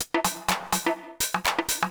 House / Loop / PERCLOOP068_HOUSE_125_X_SC2.wav
1 channel